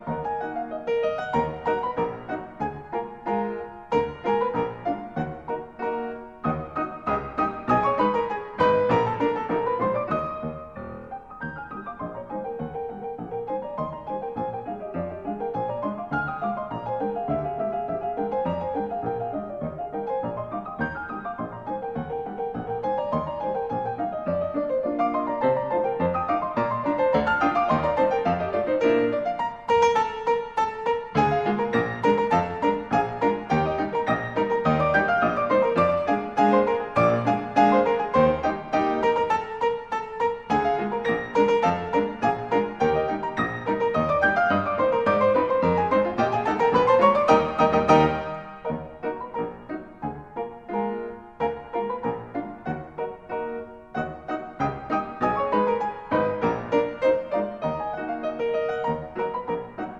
Классика
Полное собрание записей выступлений с 1919 по 1942 год.